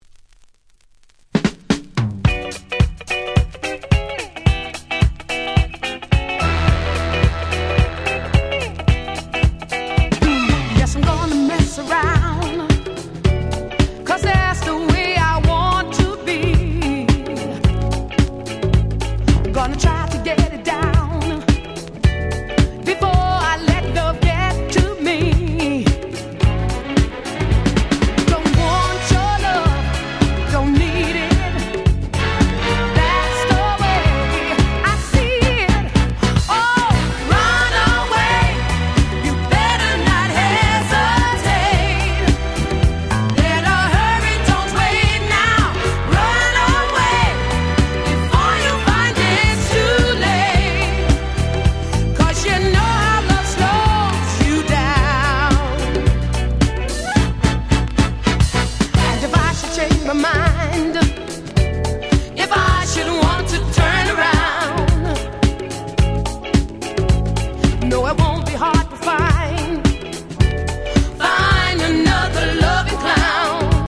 レーベルからの正規再発盤で音質もバッチリ！！
サルソウル ダンクラ ディスコ フリーソウル ダンスクラシックス Dance Classics